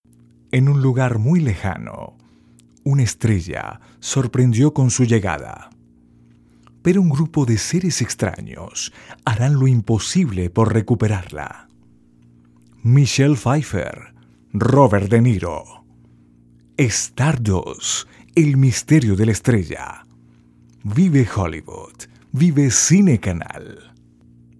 Locutor profesional con experiencia de 10 años en conduccion produccion de programas de radio.Experiencia en narracion de documentales, comerciales e identificacion para agencias de publicidad.
spanisch Südamerika
Sprechprobe: Sonstiges (Muttersprache):